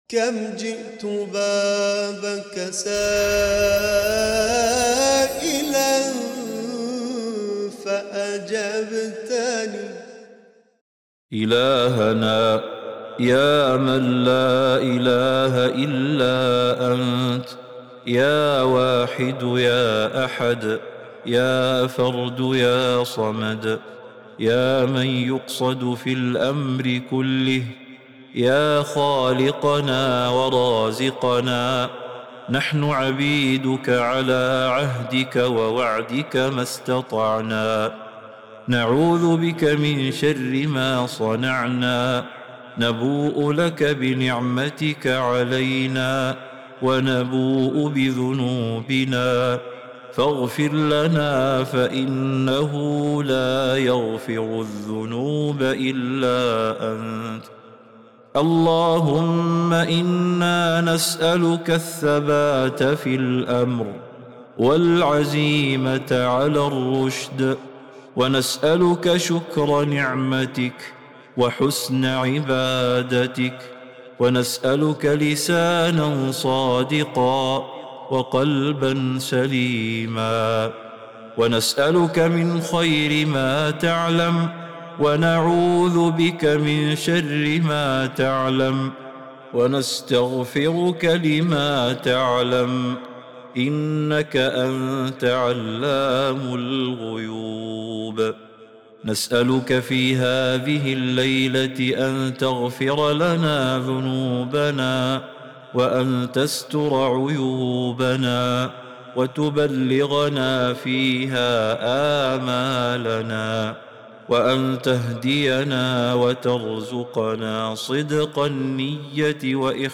دعاء جامع ومناجاة خاشعة تتضمن التوسل إلى الله بأسمائه الحسنى، والاستغفار من الذنوب، وطلب الثبات والهداية وحسن العبادة. يدعو المؤمن ربه بقلب منكسر طامعاً في مغفرته ورحمته.